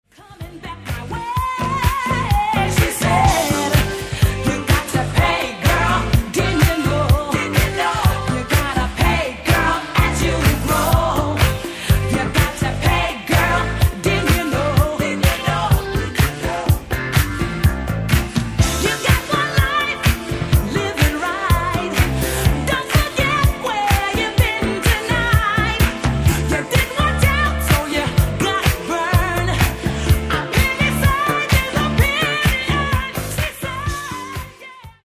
Genre:   Disco Soul